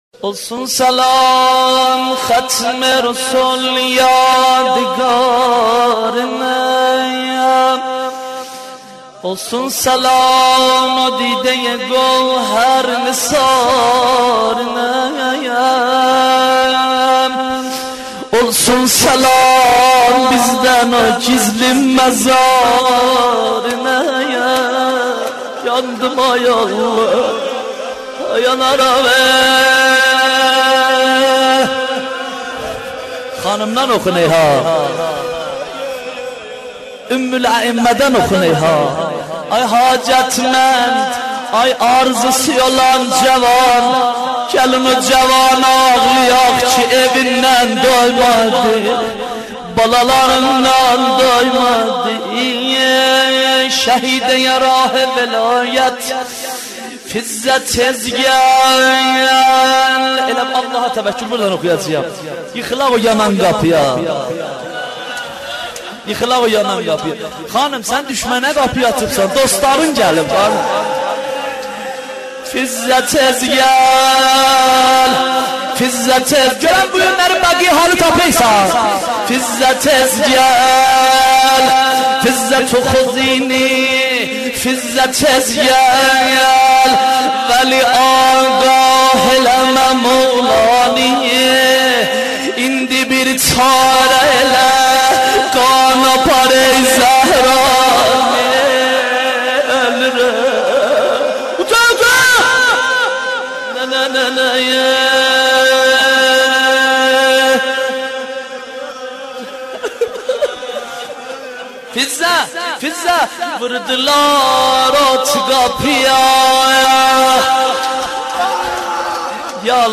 نوحه خوانی و سینه زنی به مناسبت شهادت ام الائمه حضرت زهرا(س)